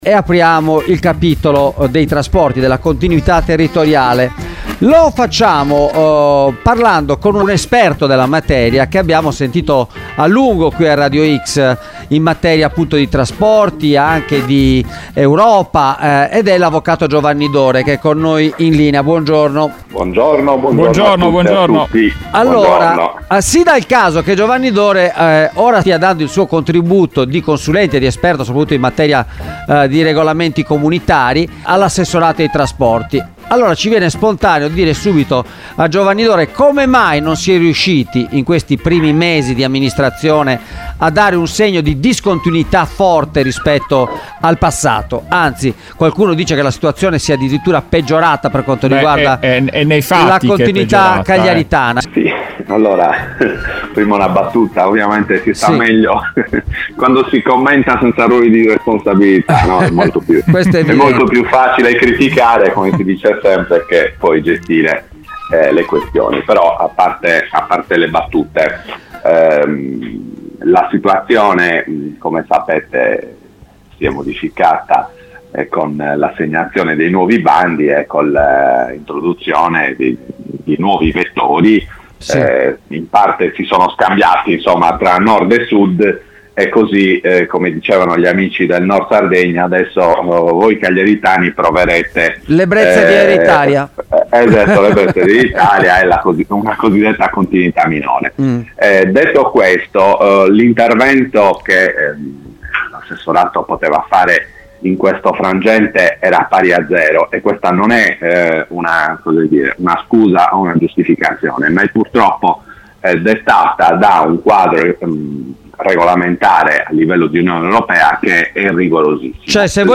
Sardegna, il punto sulla continuità territoriale tra vecchi bandi e prospettive future: intervista